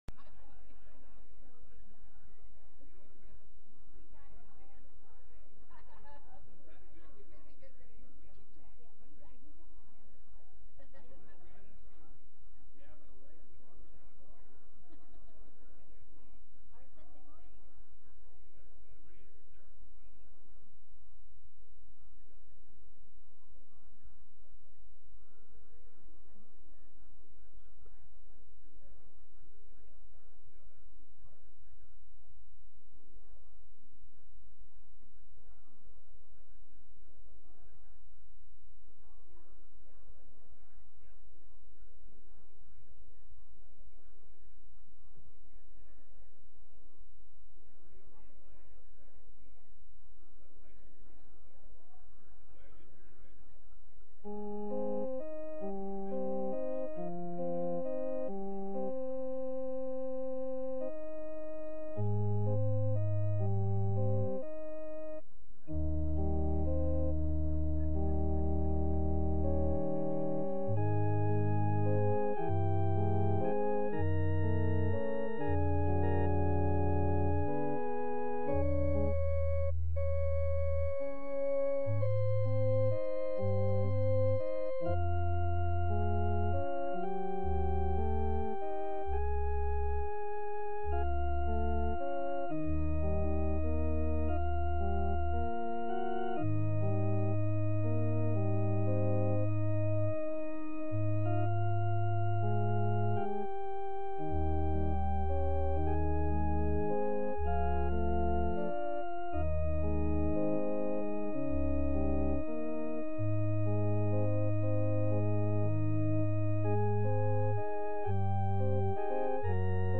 From Series: "Sunday Worship"
Sunday-Service-7-24-22.mp3